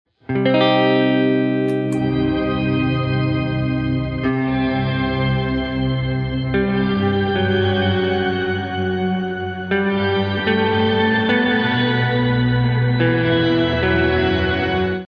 Haunting Ventris Dual Reverb preset sound effects free download
It uses one Shimmer engine set an octave up and a second Shimmer engine set an octave down.